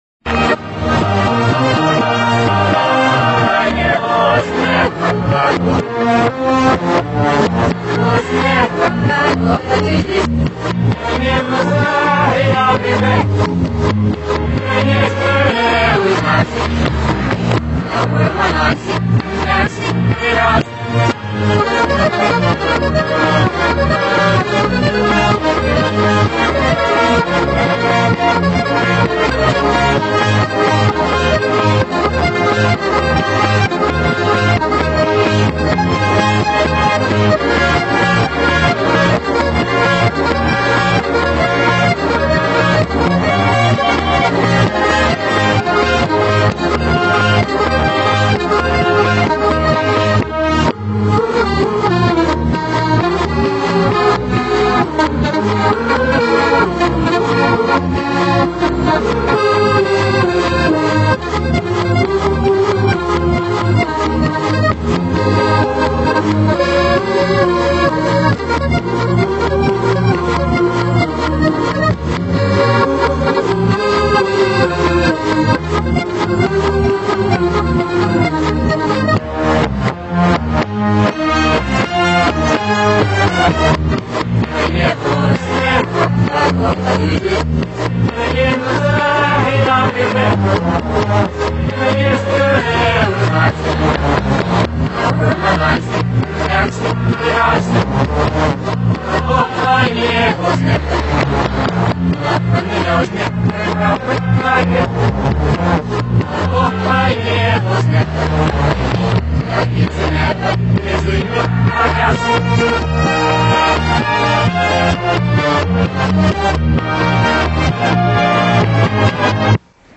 backmasked and run through autotune.